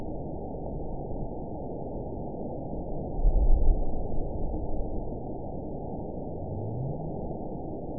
event 920551 date 03/30/24 time 00:38:28 GMT (1 year, 2 months ago) score 9.59 location TSS-AB01 detected by nrw target species NRW annotations +NRW Spectrogram: Frequency (kHz) vs. Time (s) audio not available .wav